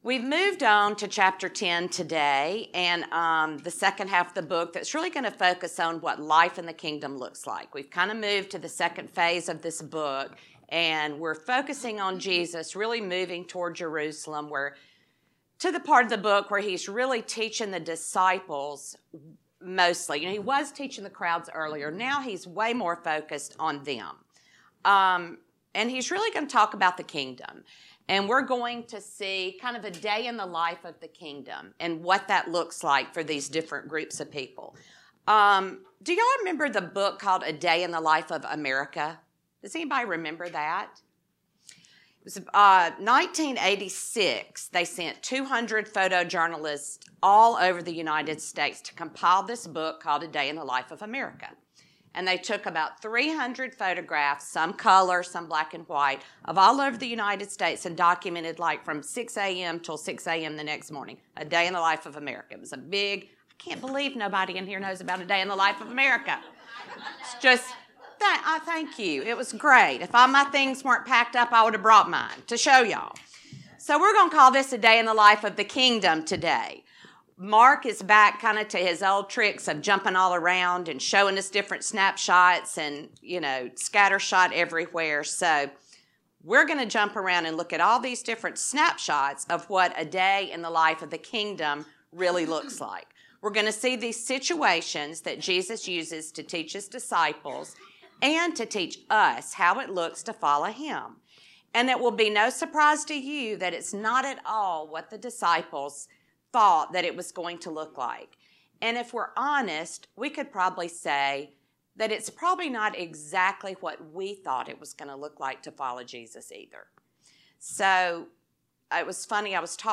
Lesson 15